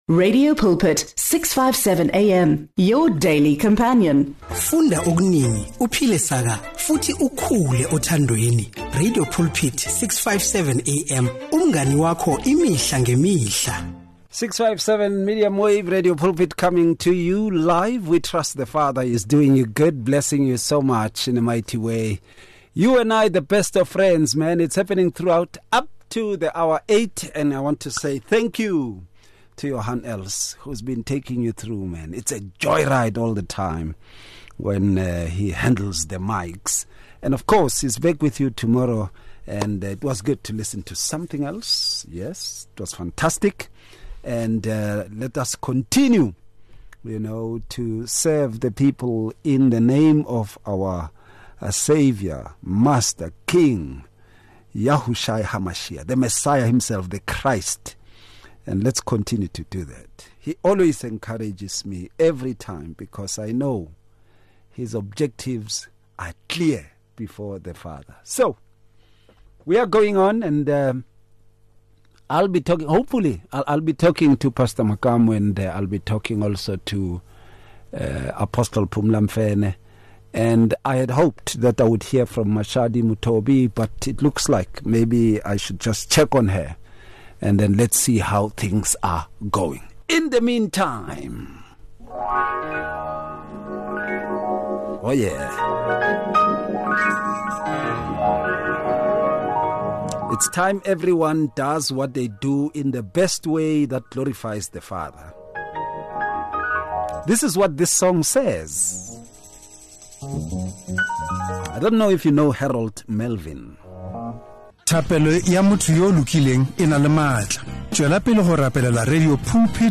MultiMedia LIVE